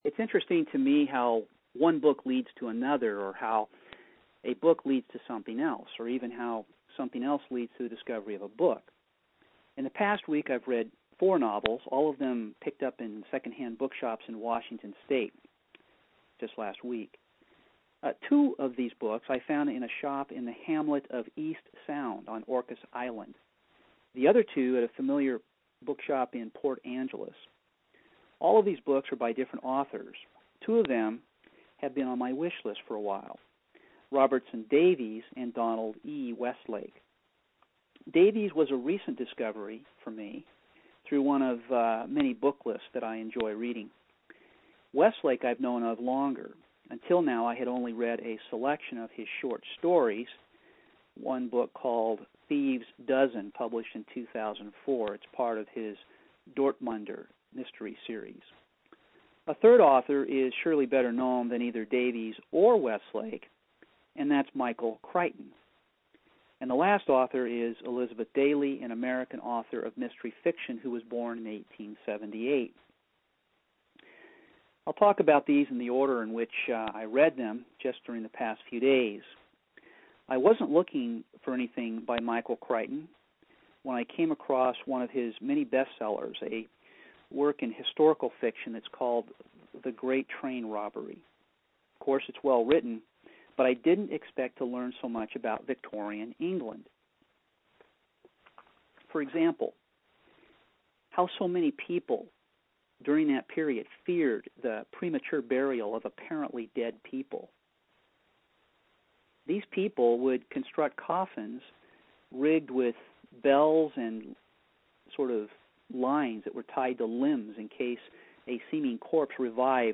I recorded the post over the phone and WordPress published it to my site. The audio quality seems OK.